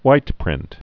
(wītprĭnt, hwīt-)